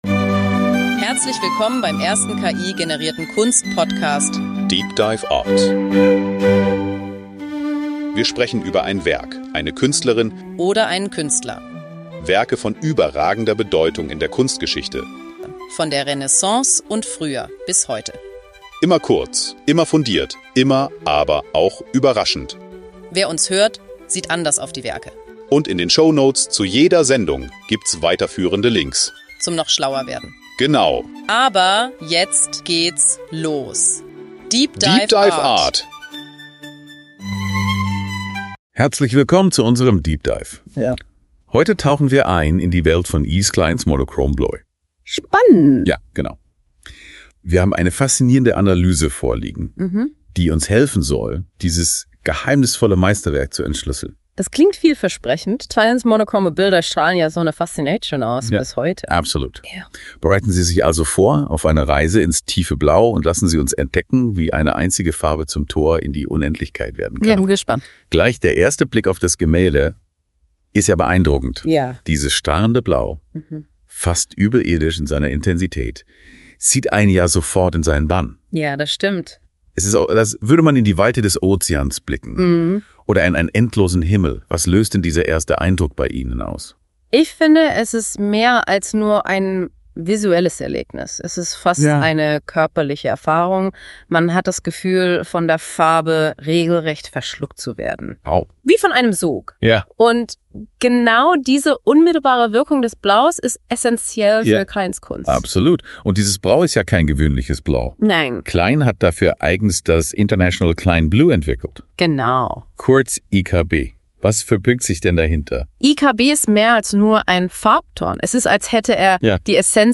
Es wird erörtert, wie Kleins Kunst die Wahrnehmung des Betrachters herausfordert und welche Emotionen sie hervorruft. DEEP DIVE ART ist der erste voll-ki-generierte Kunst-Podcast.
Die beiden Hosts, die Musik, das Episodenfoto, alles.